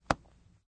pop_snap.ogg